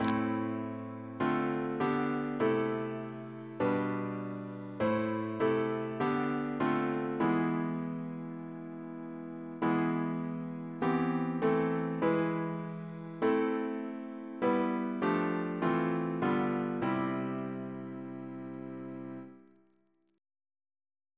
Double chant in III Composer: Chris Biemesderfer (b.1958)